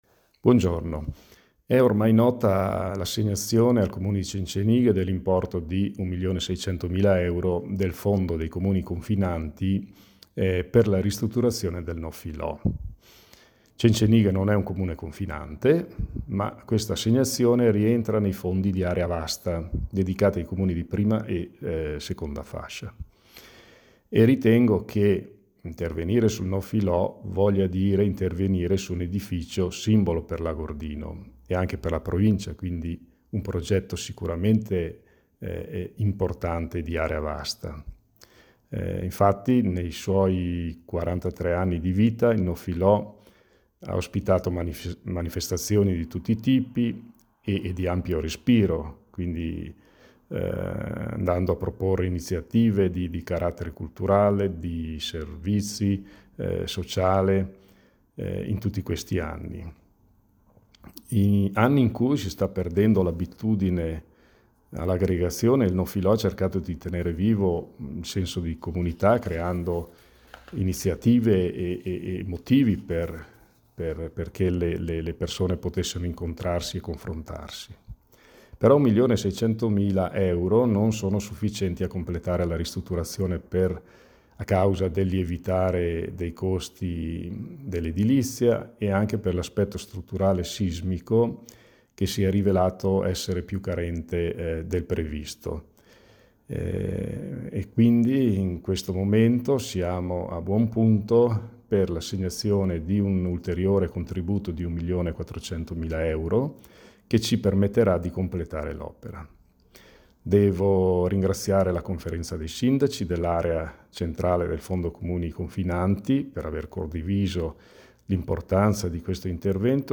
ADEGUAMENTO SISMICO E RIQUALIFICAZIONE DEL NOF FILO’: PARLA IL SINDACO MAURO SOPPELSA